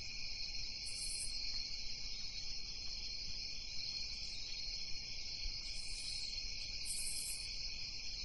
Crickets_At_NightCombo.ogg